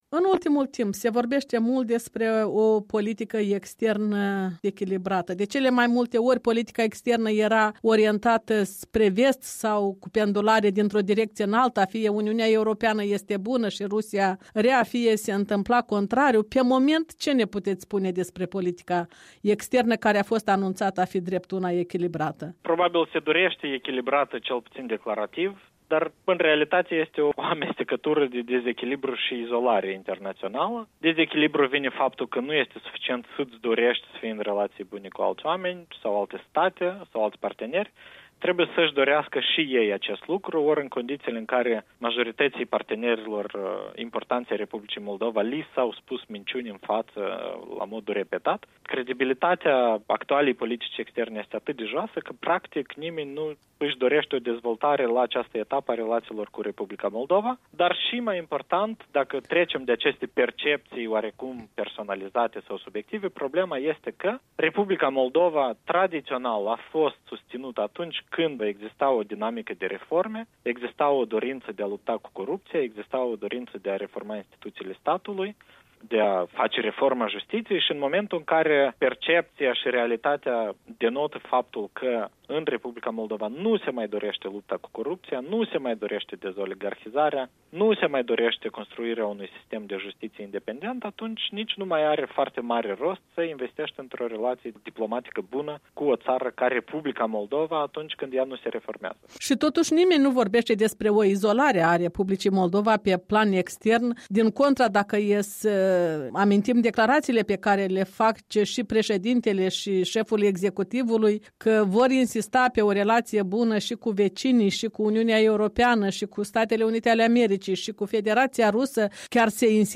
O discuție cu ex-ministrul de externe din guvernul Maiei Sandu.